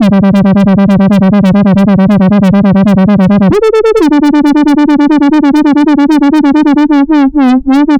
Session 08 - Retro Lead 03.wav